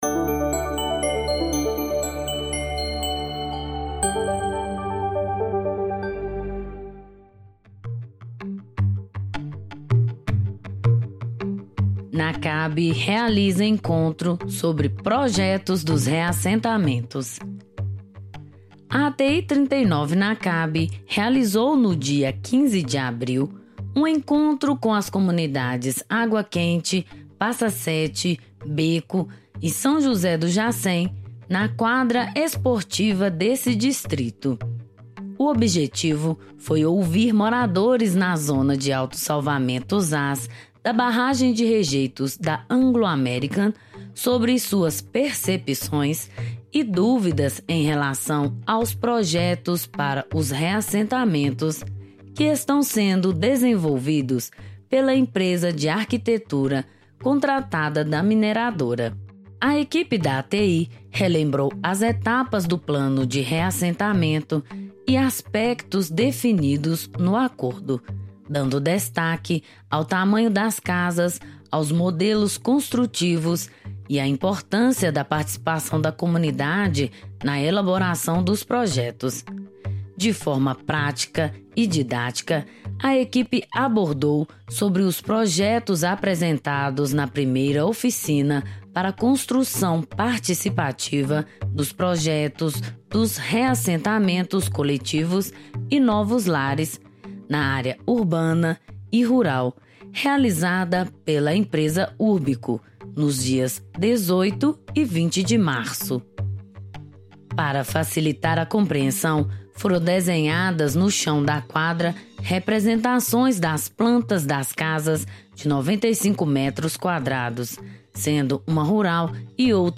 A ATI 39 Nacab realizou, no dia 15 de abril, um encontro com as comunidades Água Quente, Passa Sete, Beco e São José do Jassém, na quadra esportiva desse distrito.
Reuniao-esclarecimento-de-duvidas-sobre-casas-reassentamento.mp3